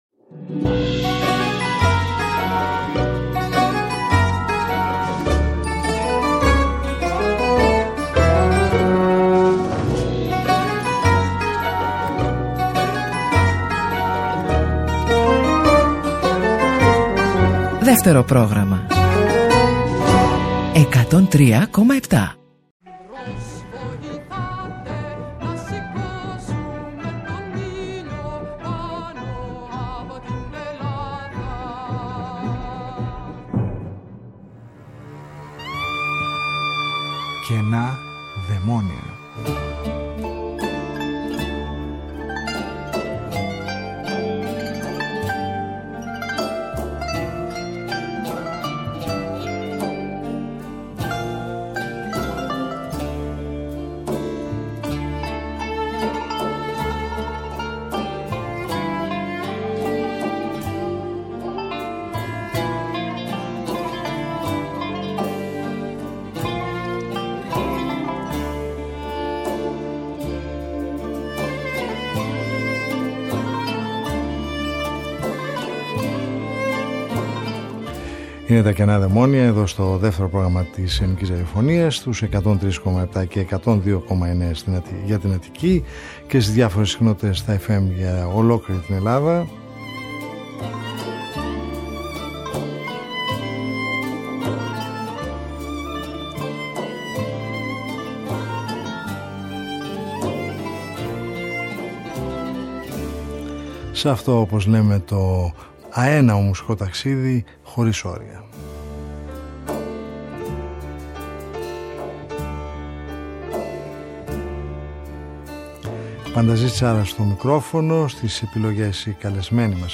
Οι μελωδίες της έκδοσης αυτής με την πιανόλα (μηχανικό πιάνο) είναι μια επιλογή τραγουδιών από διάφορες γνωστές ελληνικές οπερέτες και επιθεωρήσεις των δεκαετιών 1910-1920, συνθετών όπως ο Νίκος Χατζηαποστόλου, ο Θεόφραστος Σακελλαρίδης, ο Άγγελος Μαρτίνος και όχι μόνο.